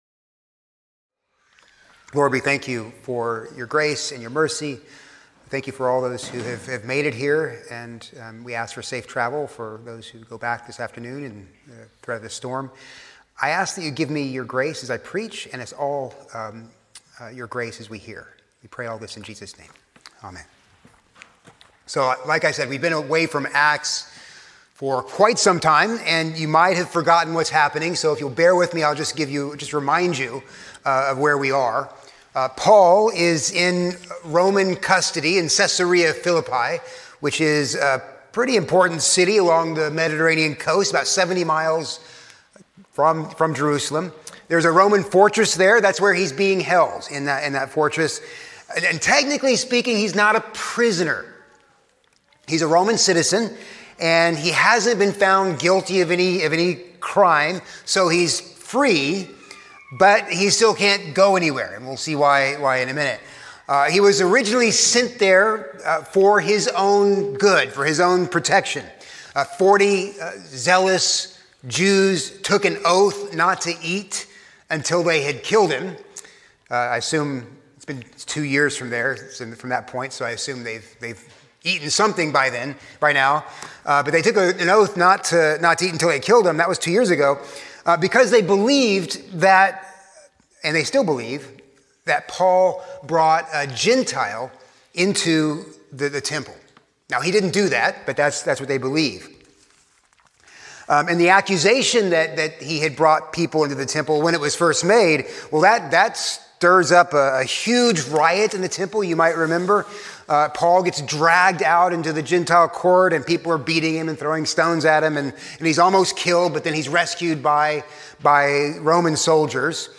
A sermon on Acts 25:1-12